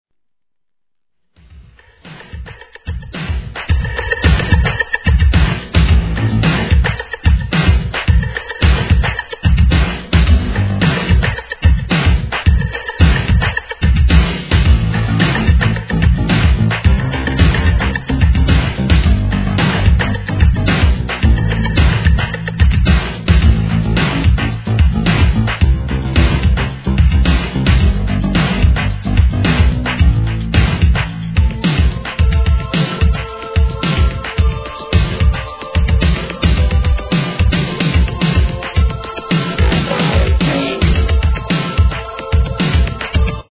HIP HOP/R&B
エレクトロッ!!!